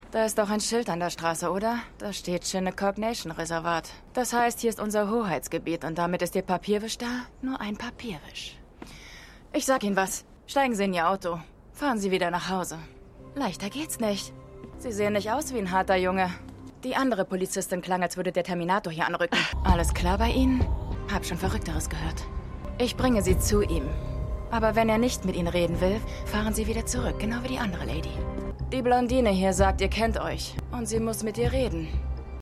Studio: Interopa Film GmbH
[INTEROPA FILM GMBH] [NETFLIX|DUBBING]